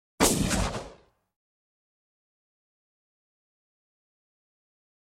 Выстрел базукой с дистанции